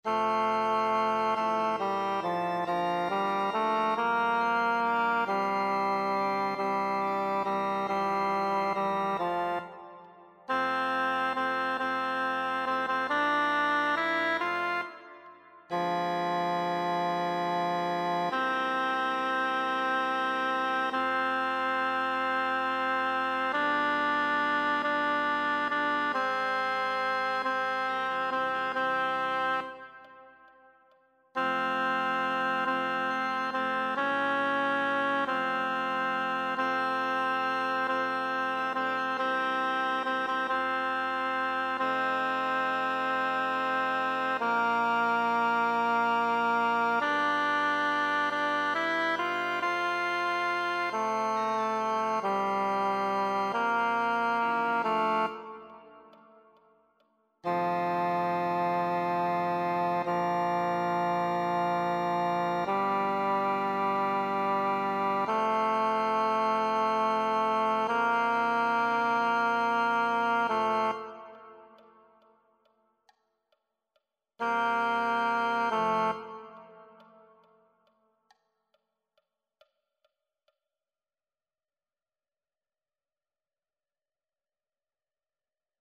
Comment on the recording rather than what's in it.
Singwoche pro Musica 2026 - Noten und Übungsdateien